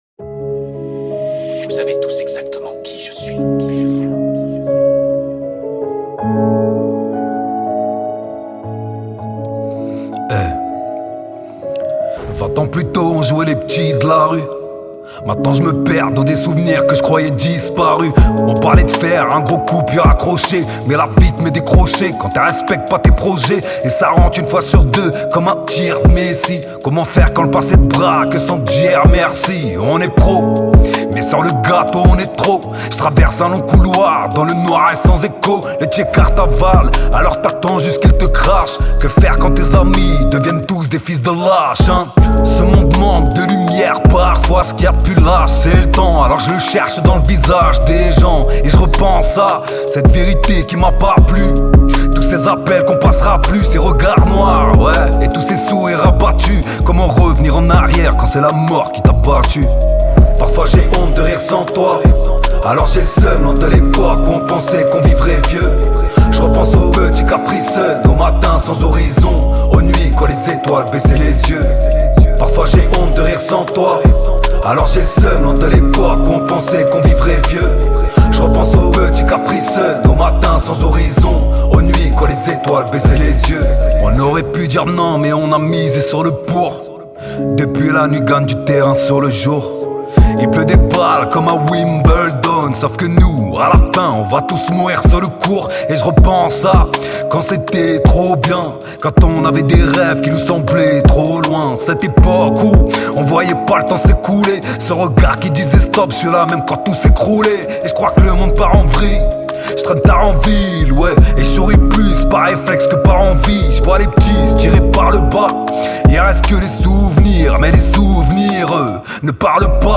Genres : french rap